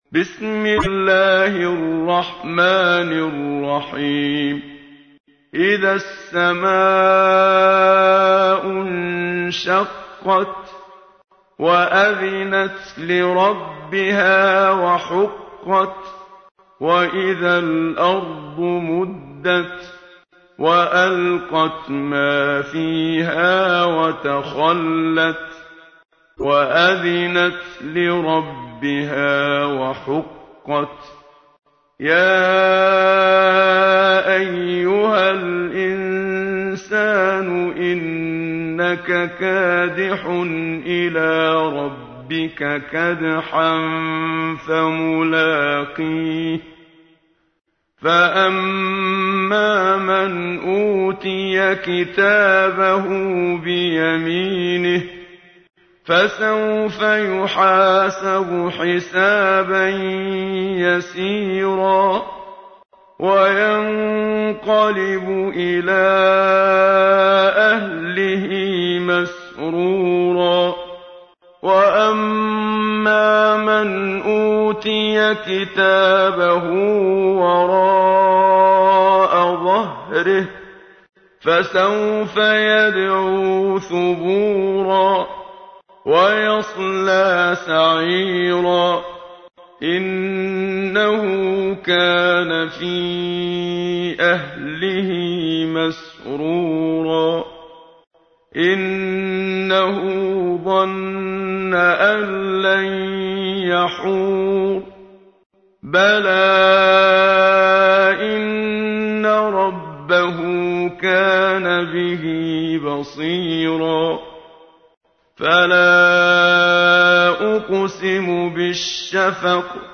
سوره ای که خواندنش حاجات شما را برآورده می کند+متن و ترجمه+ترتیل منشاوی